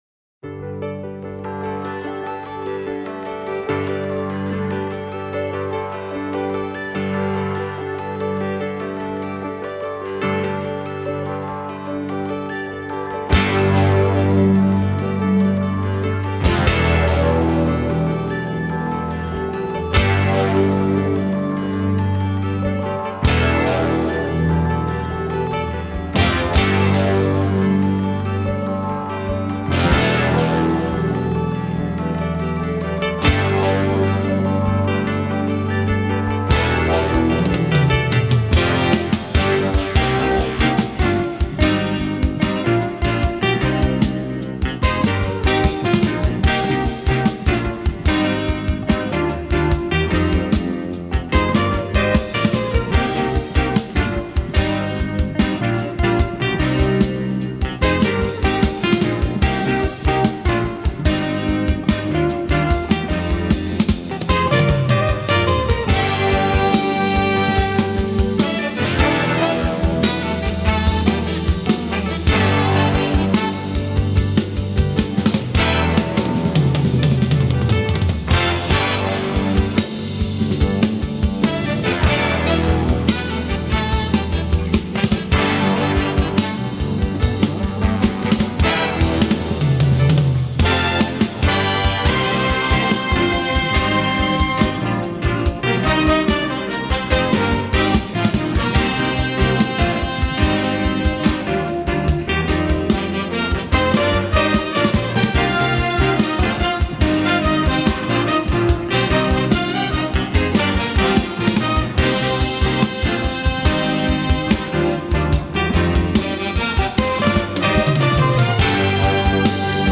Jazz Rock